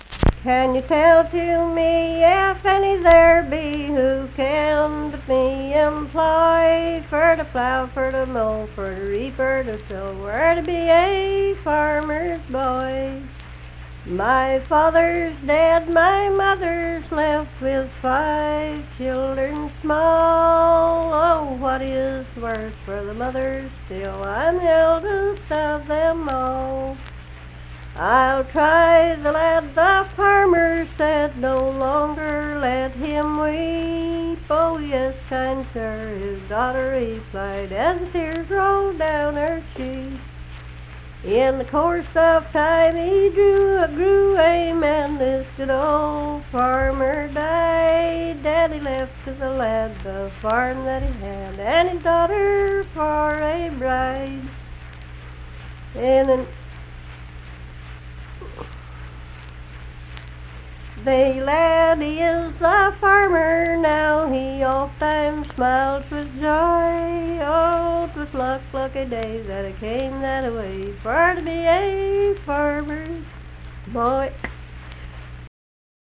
Unaccompanied vocal music performance
Verse-refrain 5(4).
Voice (sung)